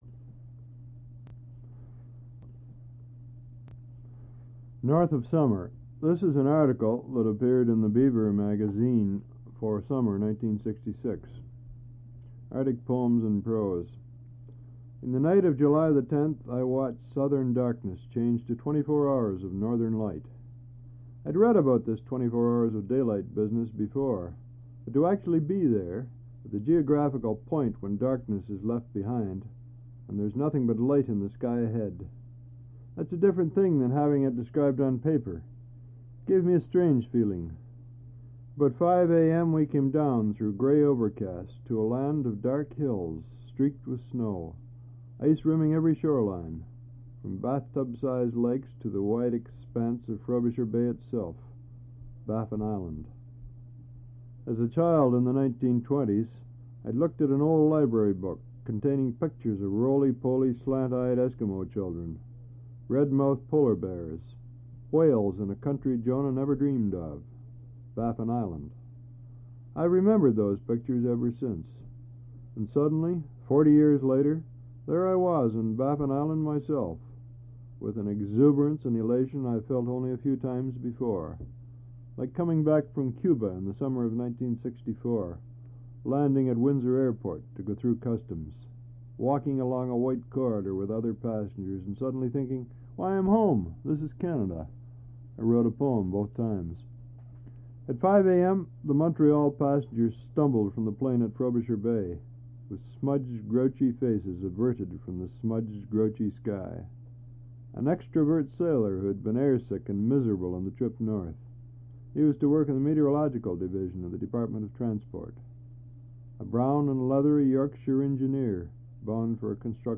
Al Purdy reads his article "North of Summer."